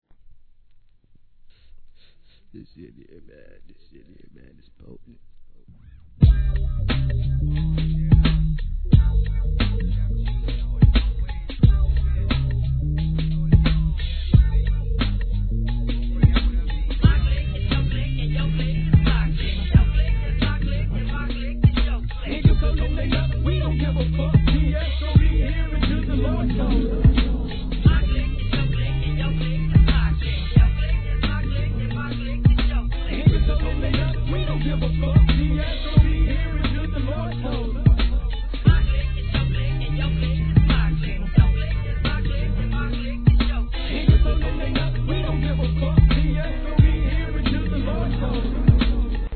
G-RAP/WEST COAST/SOUTH
メランコリックでせつない上モノが絡む哀愁系ビートに男臭いマイクリレー!!